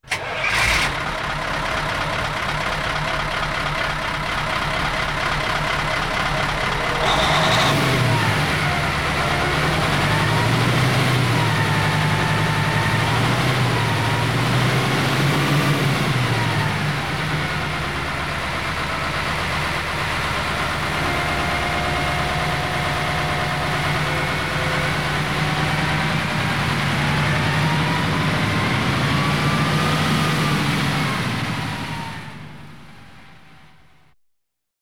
Звуки грузовика
Звук запуска дизельного мотора грузовика и добавление газа для высоких оборотов